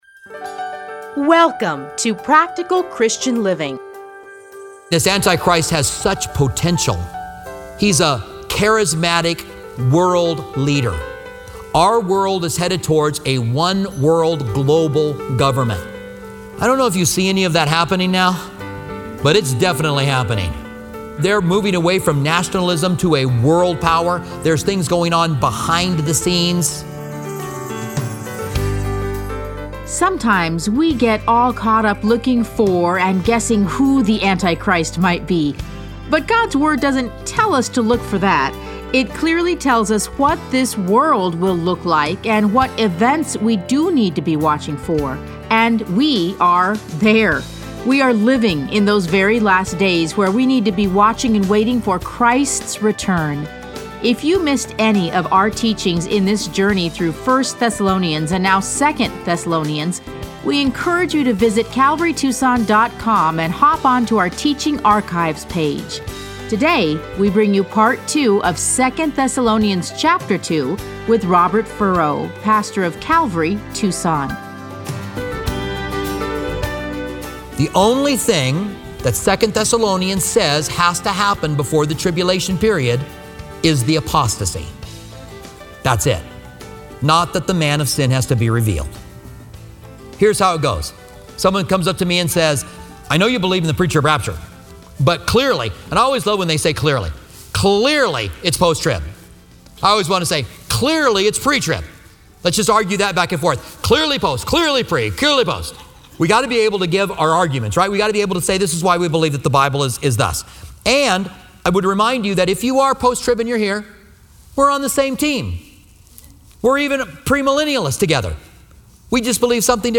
Listen to a teaching from 2 Thessalonians 2:1-17.